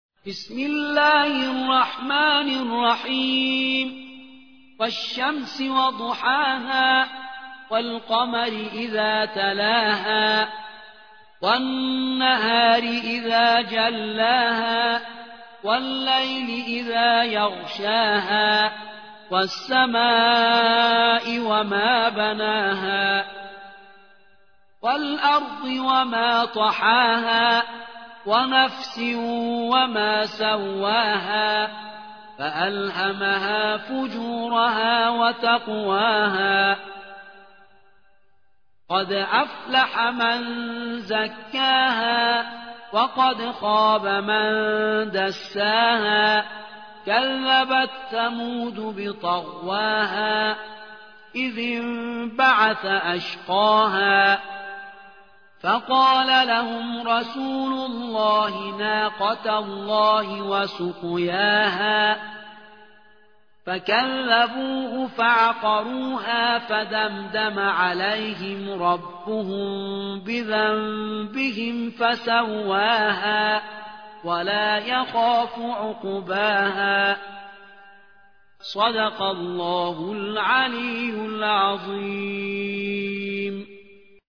91. سورة الشمس / القارئ